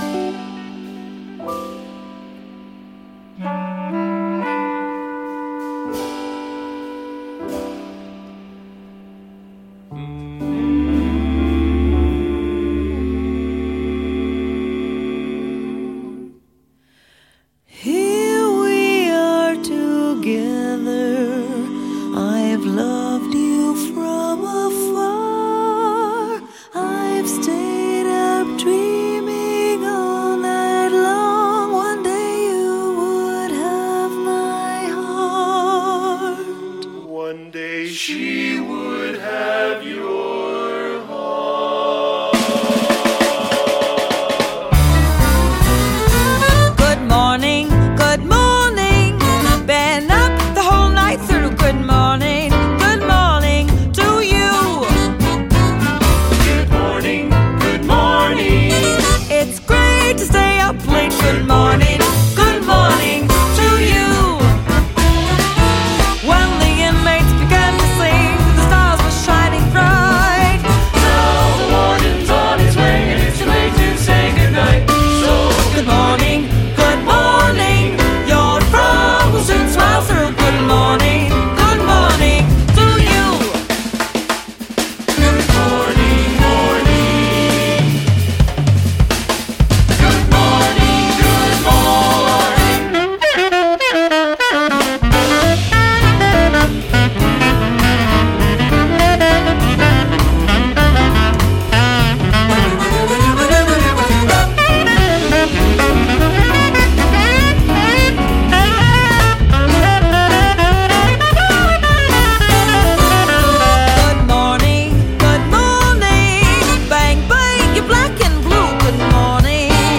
Jazz, Stage & Screen, Pop